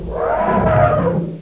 elefant.mp3